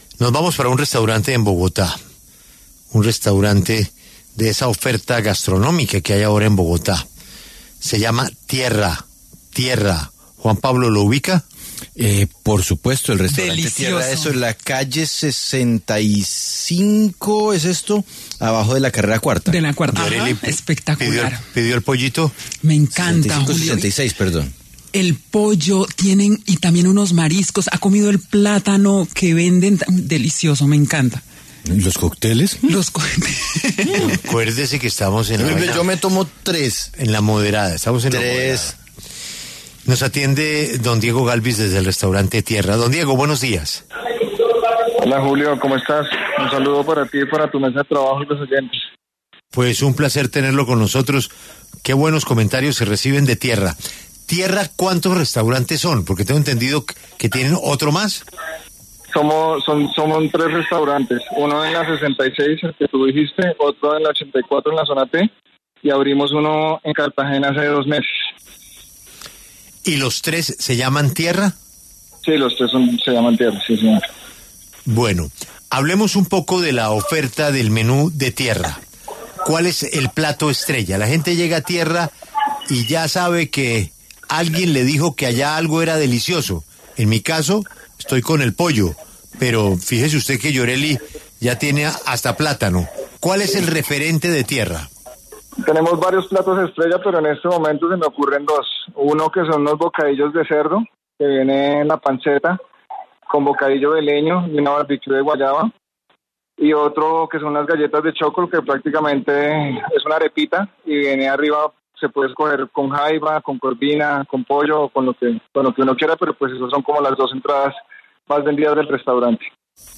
En conversación con La W